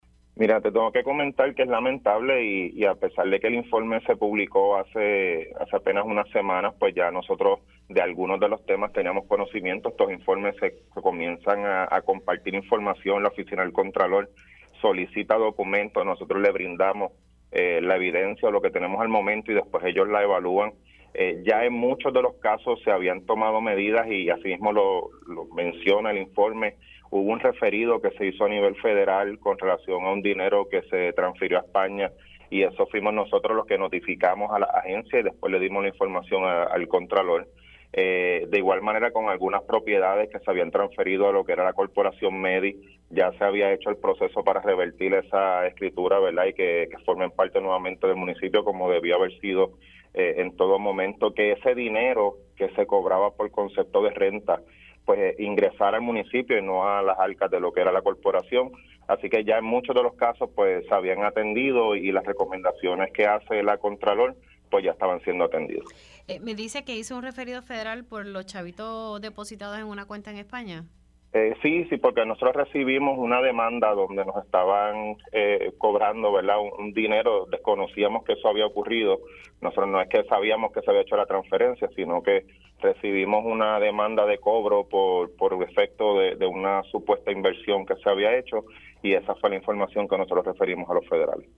El alcalde de Mayagüez, Jorge Ramos confirmó en Pega’os en la Mañana que refirieron a las autoridades federales los pagos irregulares de la Mayagüez Economic Development, Inc. (MEDI) a una cuenta bancaria en España.